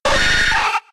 Cri de Nidorino K.O. dans Pokémon X et Y.